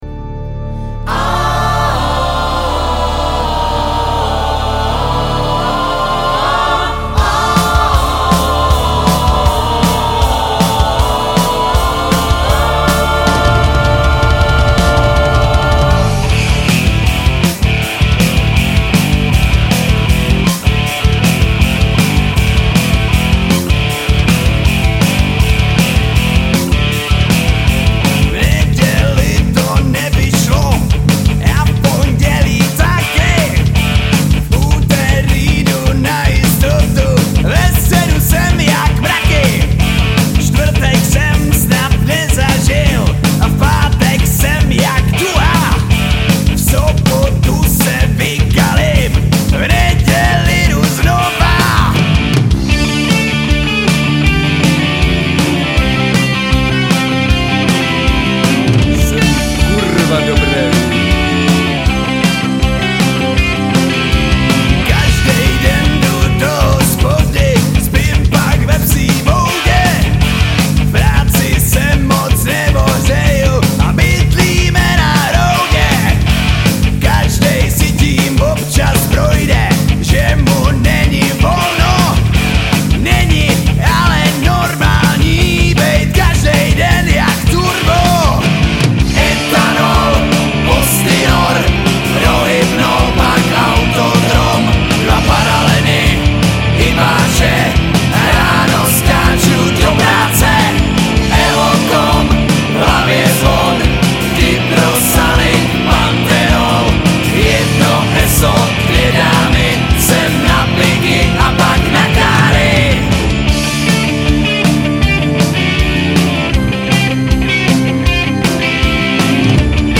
Žánr: Rock
baskytary
bicí
vokály, klávesy
vokály, kytary